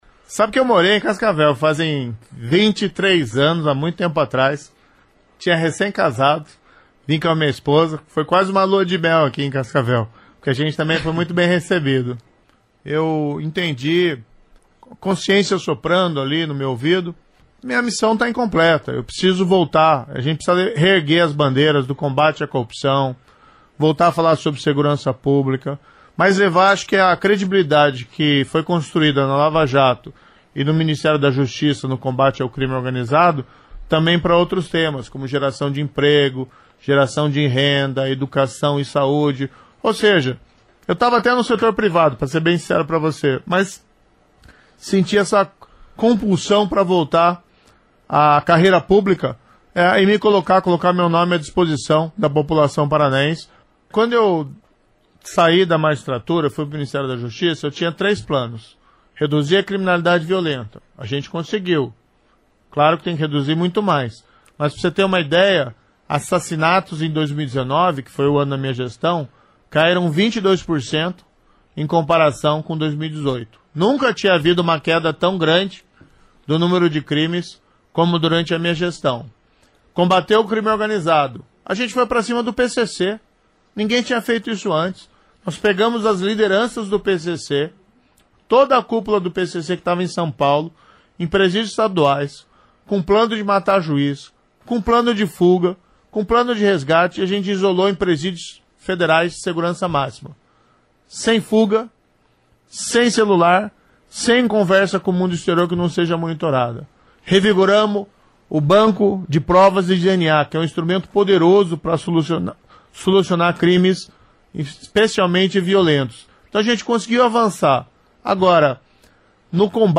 Em entrevista à CBN Cascavel nesta sexta-feria (19) o ex-juiz e ex-ministro Sérgio Moro falou do desafio que enfrenta neste ano ao concorrer a única vaga do Paraná ao Senado.
A CBN realiza entrevista (gravada) com os candidatos ao Senado, tempo de 10 minutos.